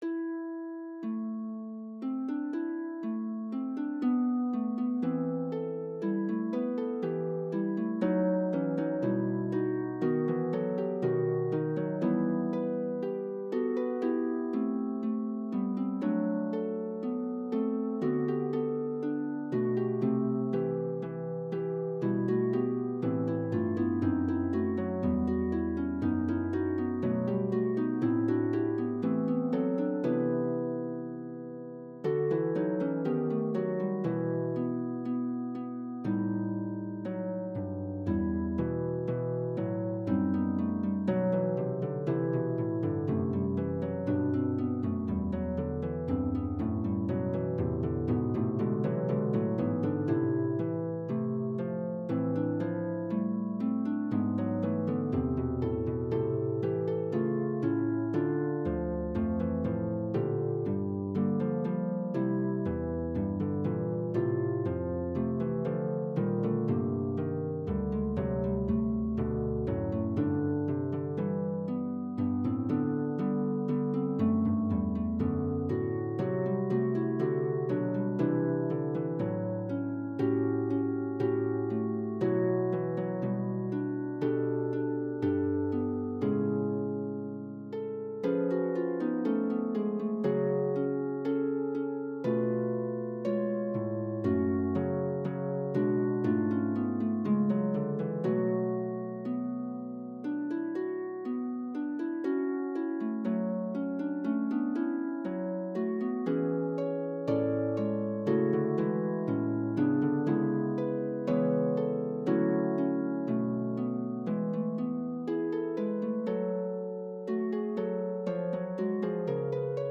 here, here, and here, but they’re all being “played” by the computer program I have and not by a human, so I’ll have much better recordings in the future.